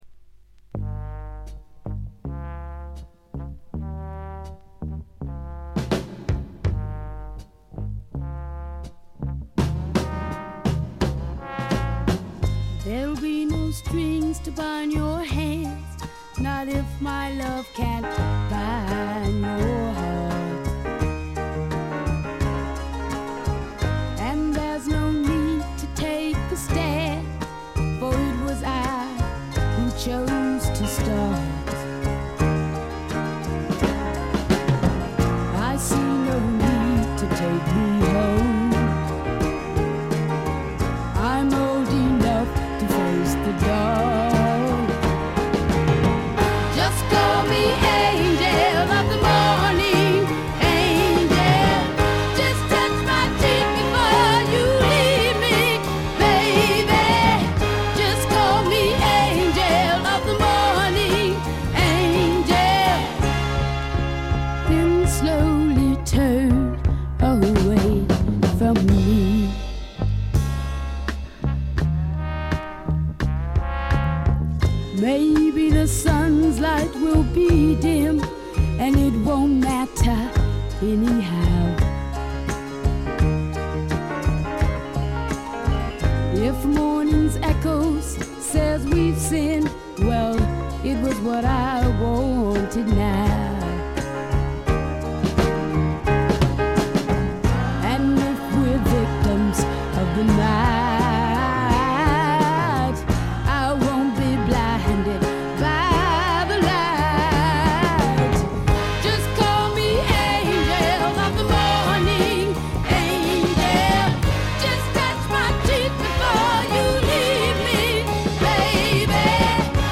全体にバックグラウンドノイズ、チリプチ多め。
試聴曲は現品からの取り込み音源です。